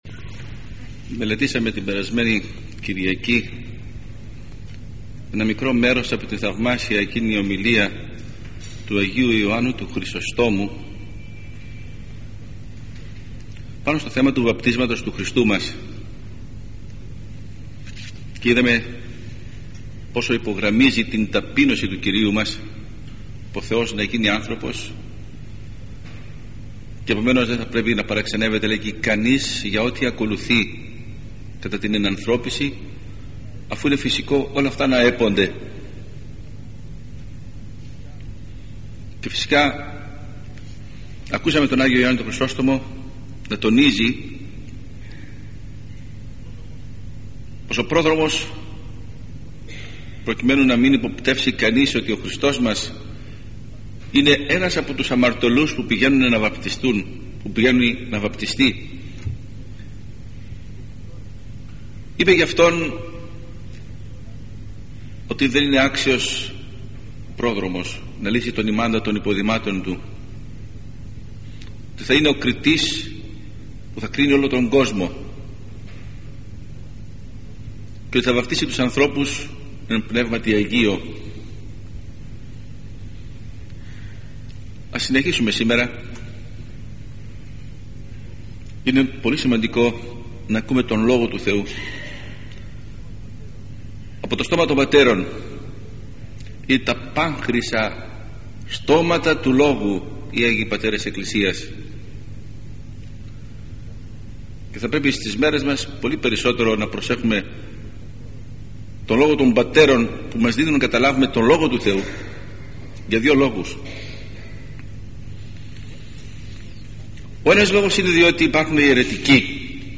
Ἑσπερ. κήρυγ.(Ἱ. Ν. Ταξιαρχῶν Ἐρμιόνη)